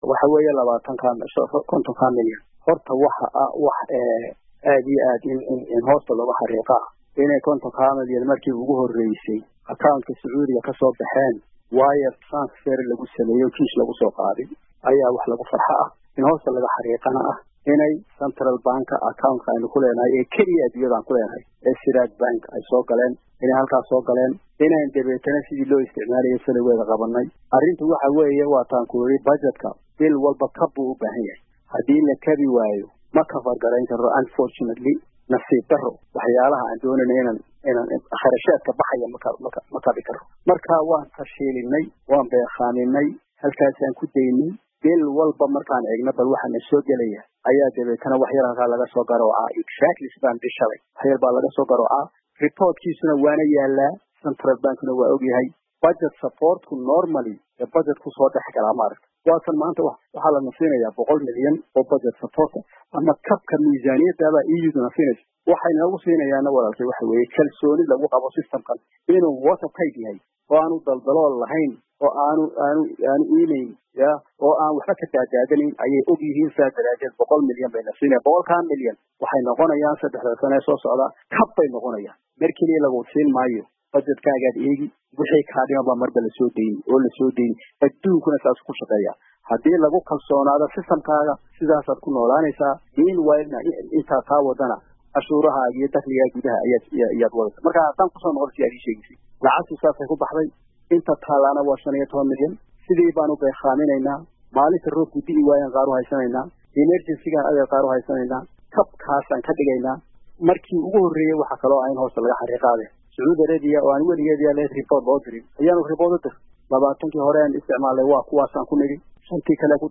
Wareysiga wasiirka maaliyadda